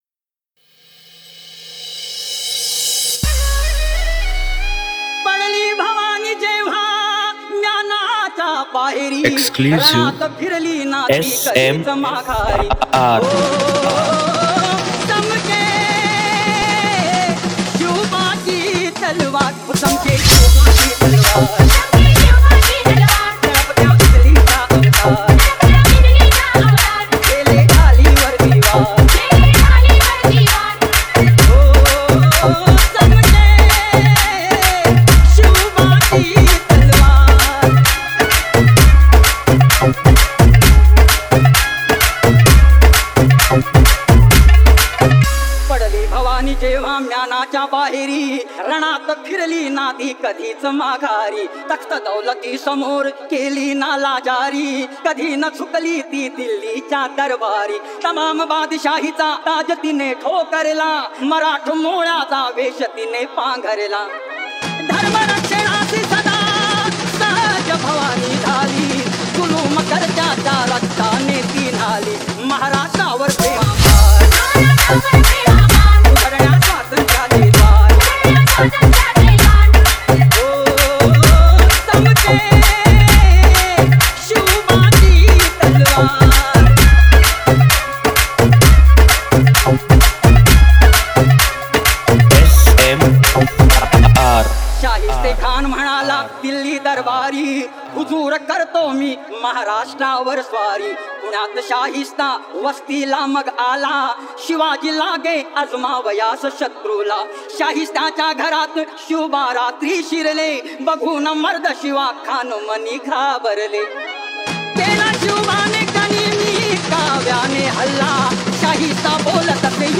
#1 New Marathi Dj Song Album Latest Remix Releases